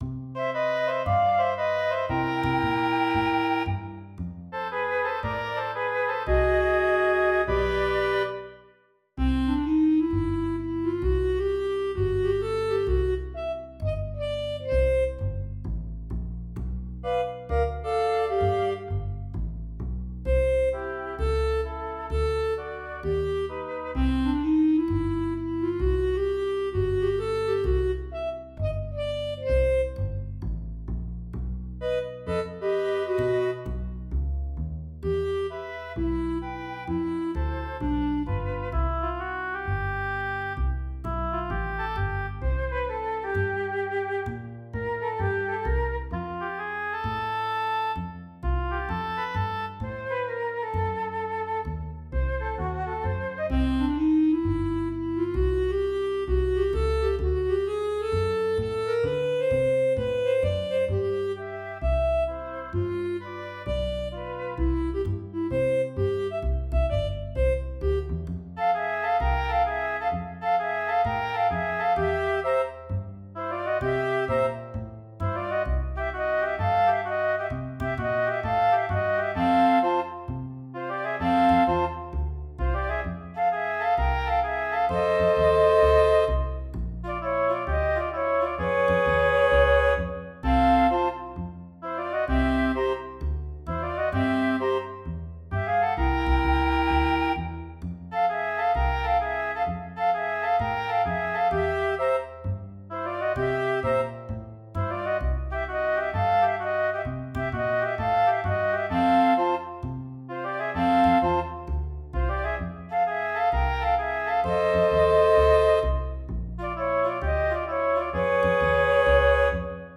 All the instrumental works are recorded through Finale: notation software that gives me the sound of virtually any instrument, from simple piano to a symphony orchestra, and astonishingly lifelike.
Flute, oboe, clarinet, and string bass
Here is another swing thing that I wrote for two of my instrumentalist friends.
Each phrase in the melody is followed by what, in the score, looks like its mirror image.